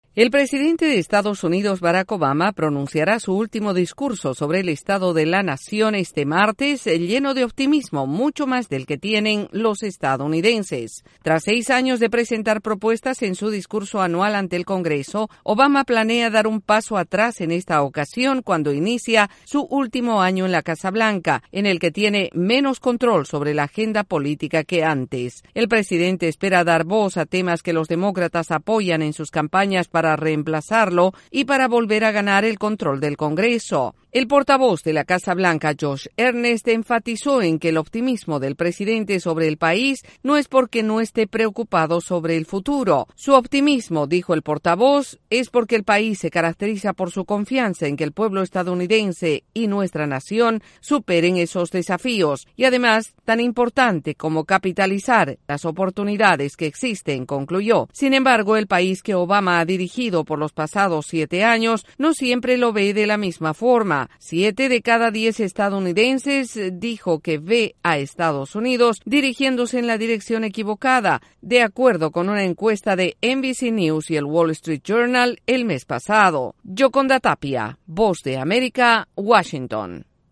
En su último discurso sobre el estado de la Nación, el presidente Barack Obama hablará con optimismo sobre el futuro y las oportunidades. Desde la Voz de América en Washington informa